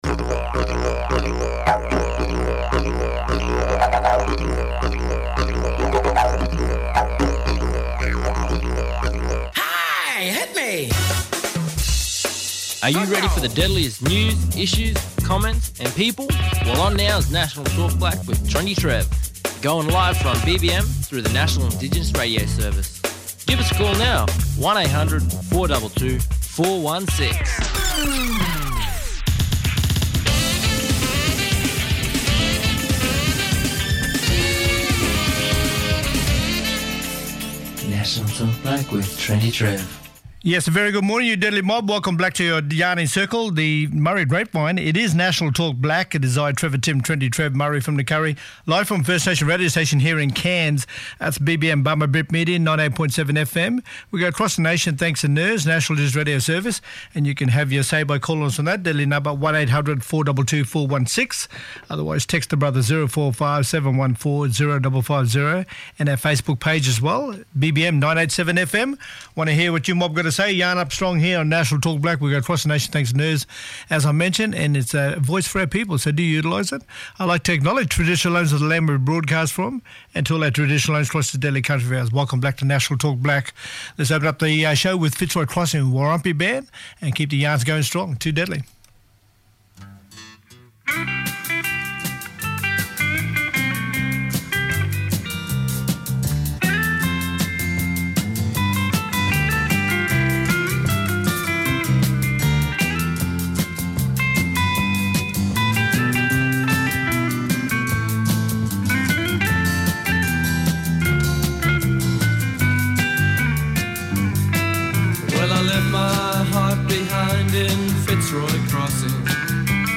in the studio